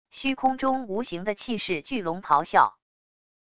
虚空中无形的气势巨龙咆哮wav音频